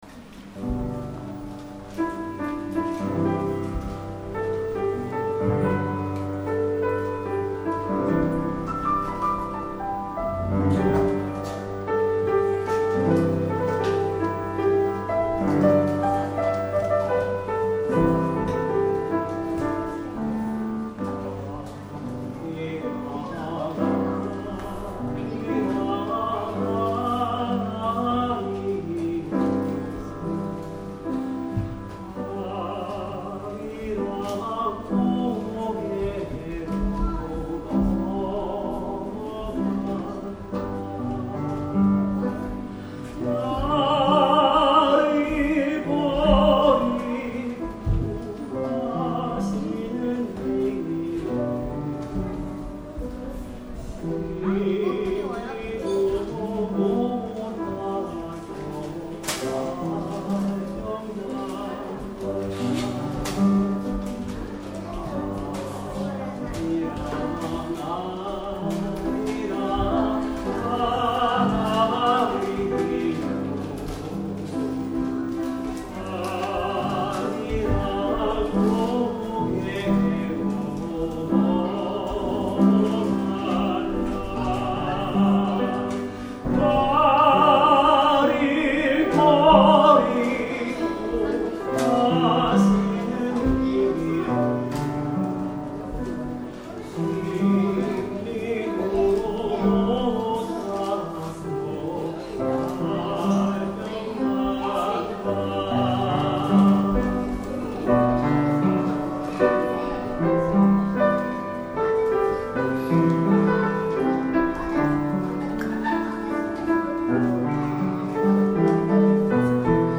Place: Kashiwa Shalom Church
伴奏付 Solo